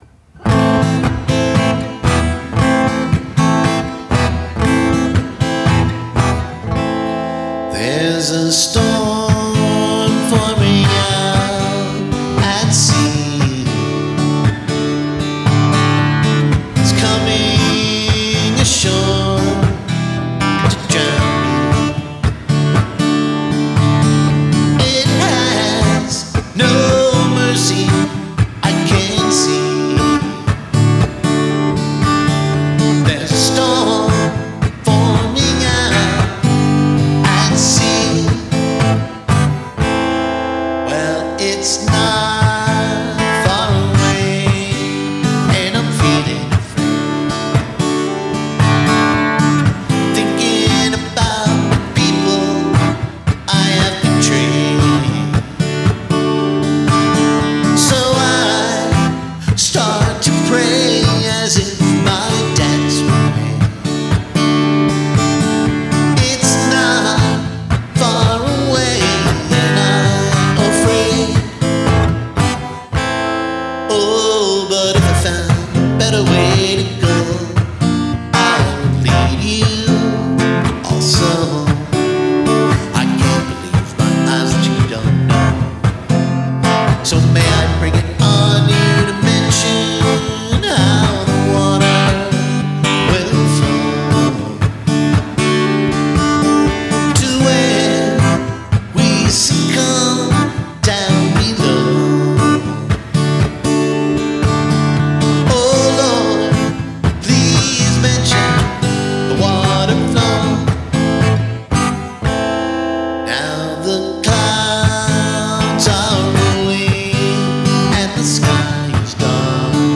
Tempo 116
Scratch